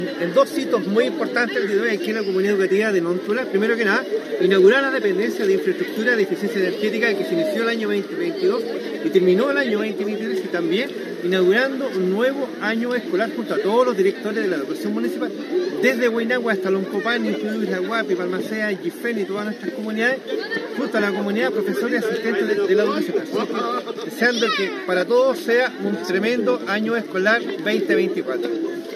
Con la ceremonia de inauguración de obras, se realizó el hito inicial del año escolar 2024 para la comuna de Futrono, relevando la inversión del Ministerio de Educación que supera los 277 millones.